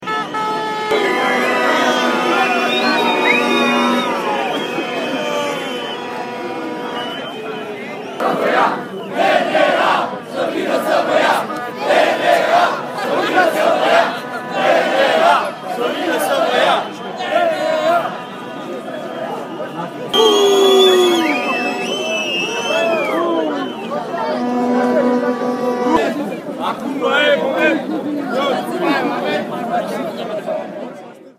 În ritmul sloganului de galerie protestatarii au strigat „Nu vă fie frică țara se ridică!”, „Toate partidele aceeași mizerie!”, „Nu plecăm acasă, hoții nu ne lasa!”.
Proteste și lozinci la București...